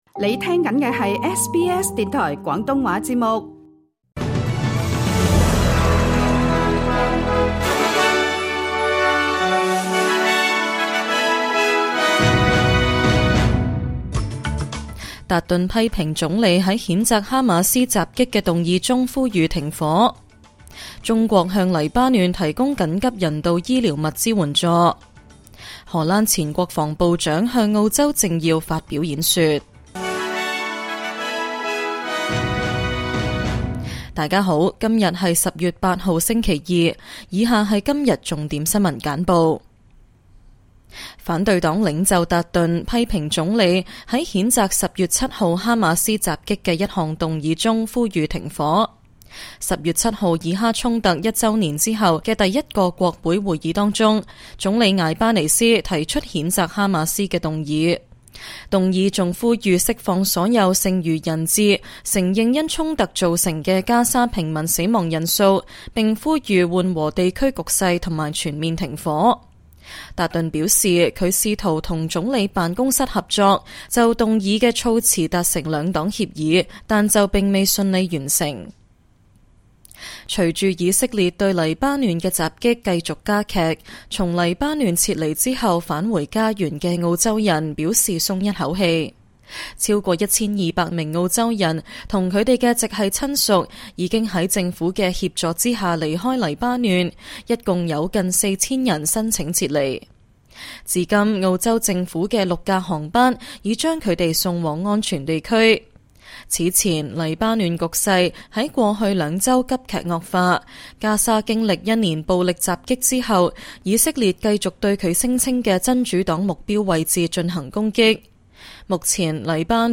請收聽本台為大家準備的每日重點新聞簡報。
SBS 廣東話晚間新聞